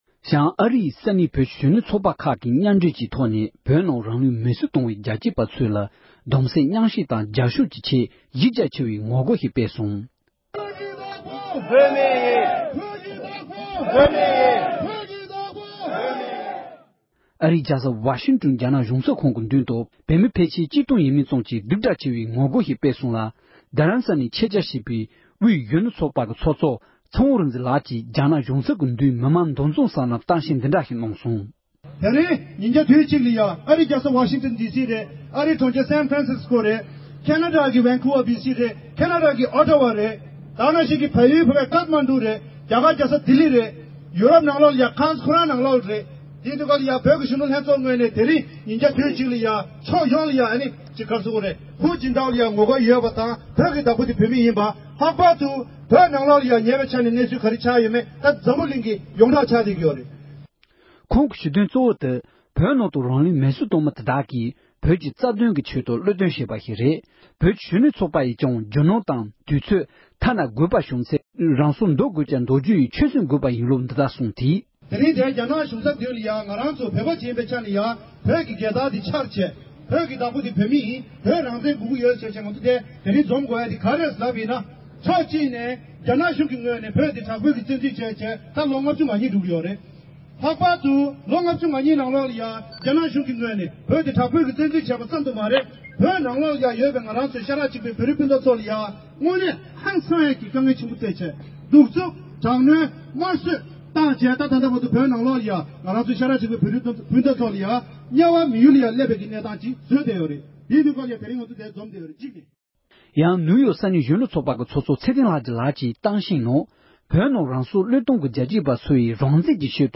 གནས་ཚུལ་ཕྱོགས་སྒྲིག་དང་སྙན་སྒྲོན་ཞུས་པ་ཞིག